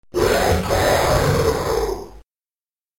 splatter_hellchaos.mp3